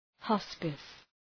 Προφορά
{‘hɒspıs}